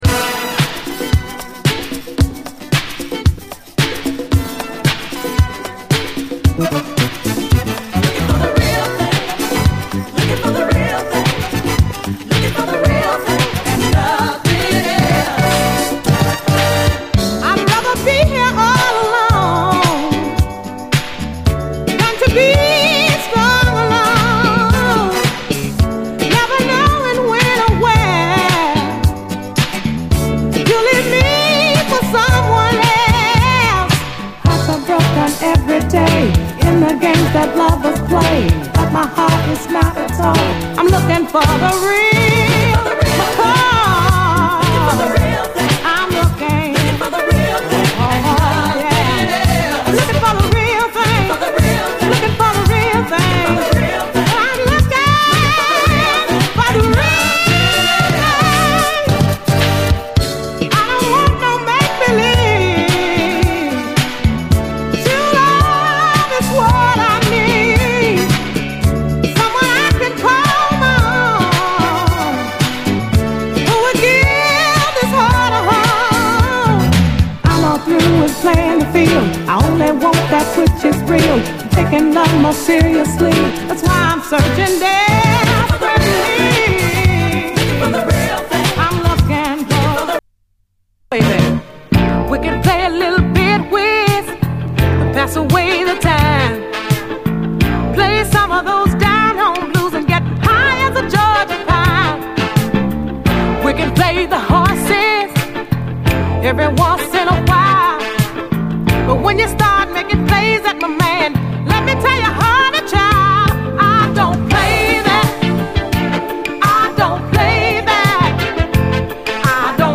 SOUL, 70's～ SOUL, DISCO
メンフィス産80’Sレディー・モダン・ソウル
南部の名レディー・ソウル・シンガーのメンフィス産80’Sモダン・ソウル！爽やかなビューティフル・モダン・ダンサー
ソウルフルな渋みが凝縮したメロウ・モダン・ソウル